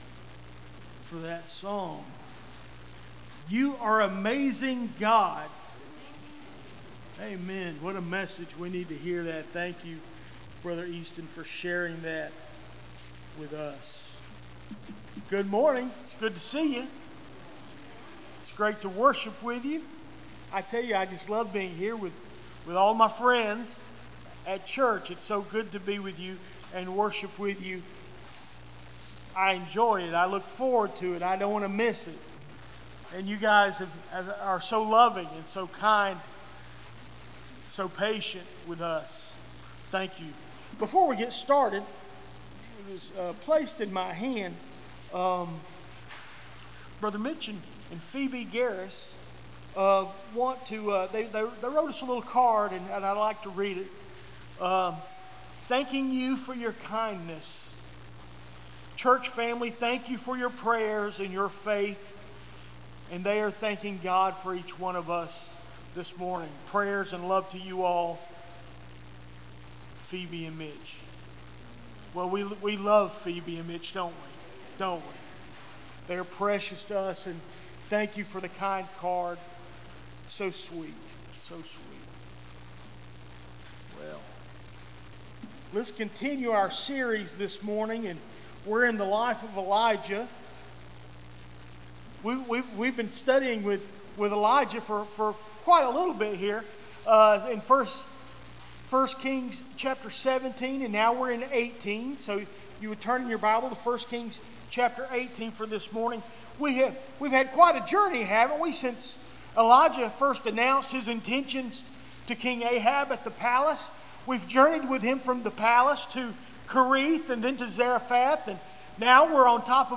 Recent Sermons - Doctor's Creek Baptist Church